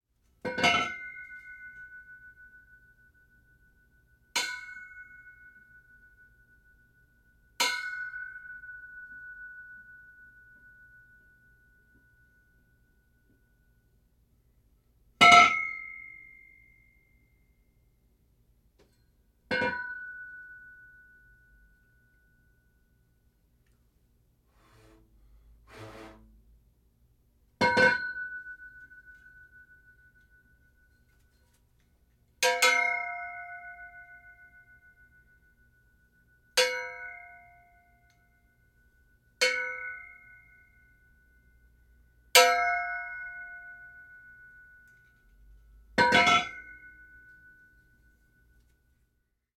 poles_ringing
ding impact metal pole ring sound effect free sound royalty free Sound Effects